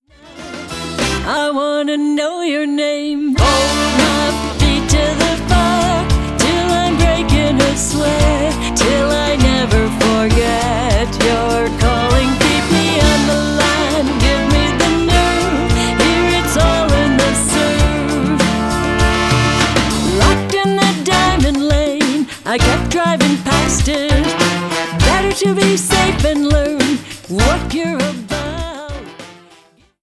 Lead Vocal
Percussion
Trombone
Trumpet
Saxophone